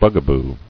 [bug·a·boo]